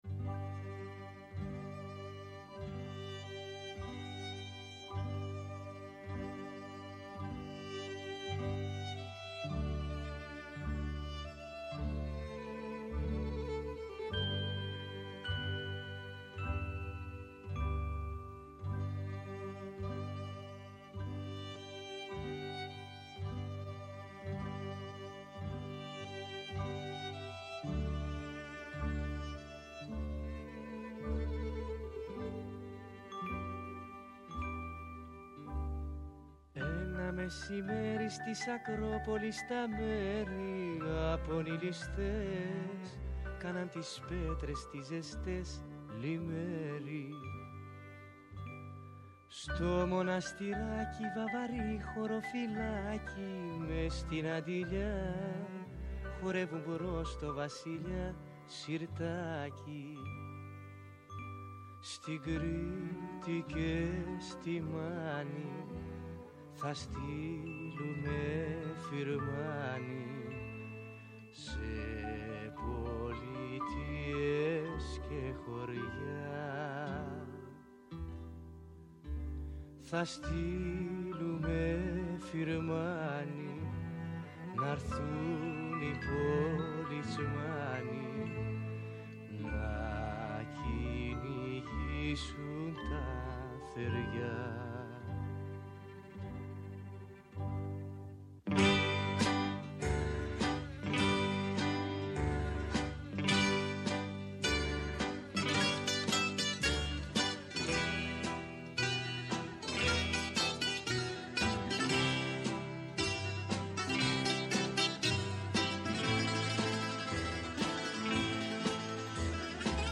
Ενστάσεις, αναλύσεις, παρατηρήσεις, αποκαλύψεις, ευχές και κατάρες, τα πάντα γίνονται δεκτά. Όλα όσα έχουμε να σας πούμε στο Πρώτο Πρόγραμμα της Ελληνικής Ραδιοφωνίας, Δευτέρα έως και Πέμπτη, 1 με 2 το μεσημέρι.